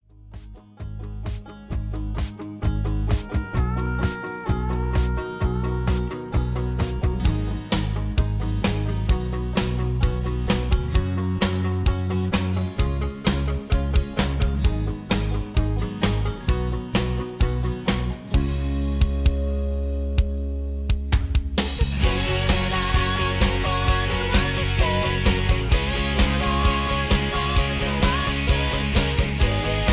Key-Gb) Karaoke MP3 Backing Tracks